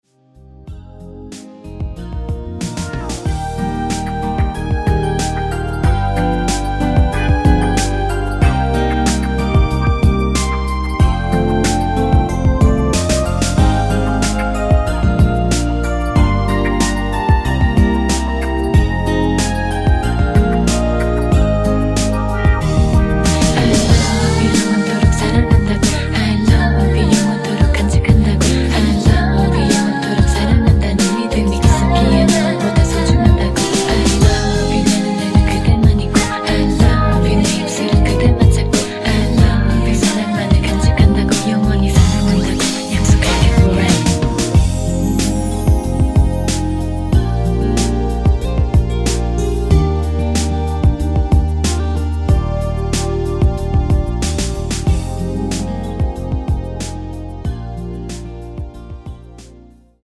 Eb
앞부분30초, 뒷부분30초씩 편집해서 올려 드리고 있습니다.
중간에 음이 끈어지고 다시 나오는 이유는
축가 MR